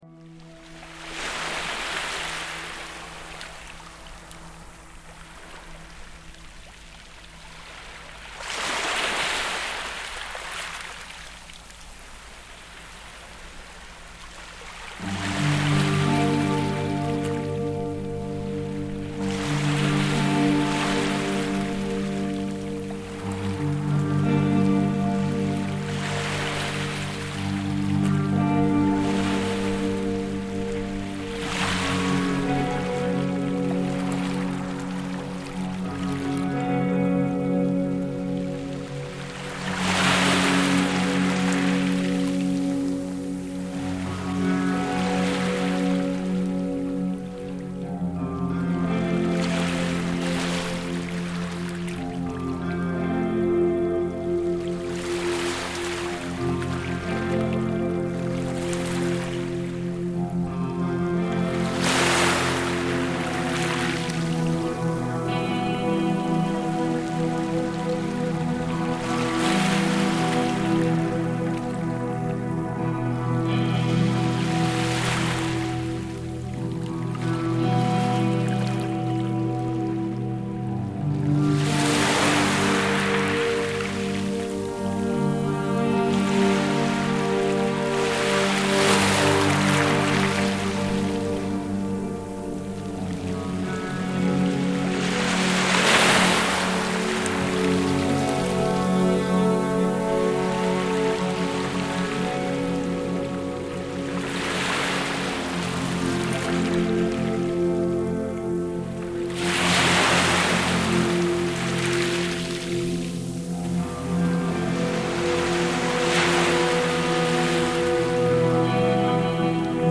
Waves on Cromarty beach reimagined